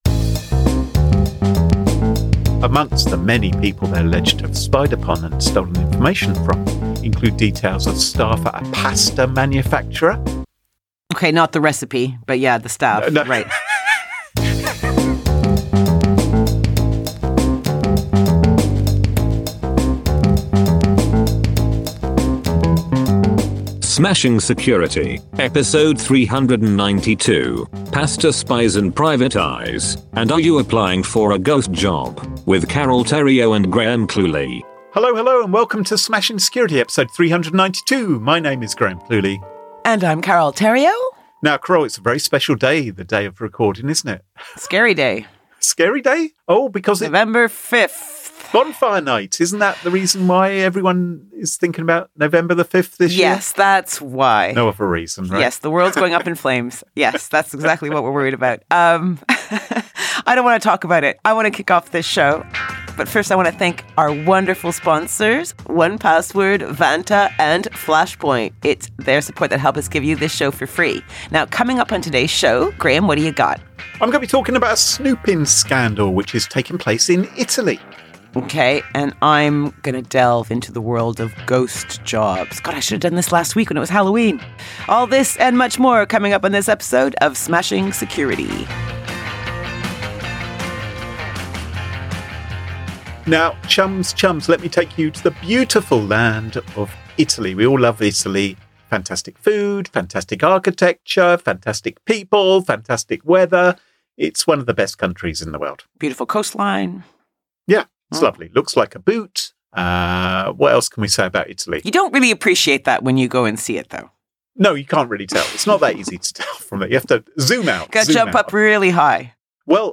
Warning: This podcast may contain nuts, adult themes, and rude language.